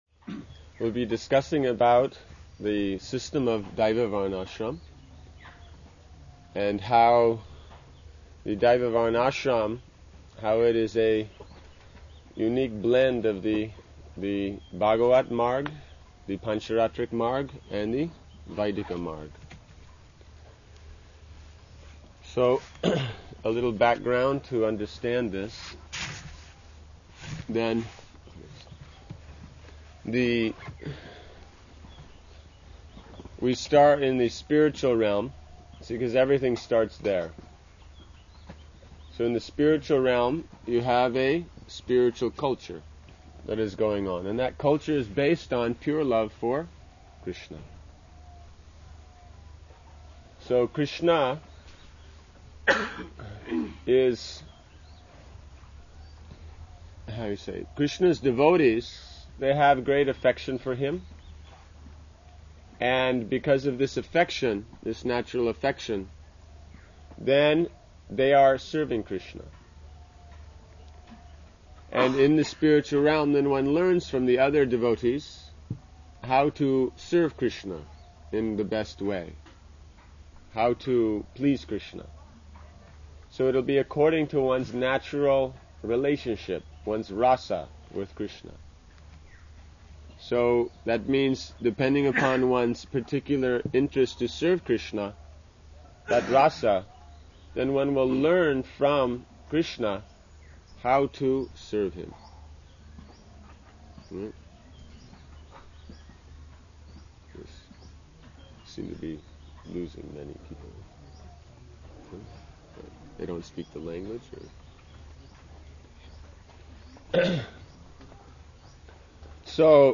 Seminar on Varṇāśrama given in Māyāpura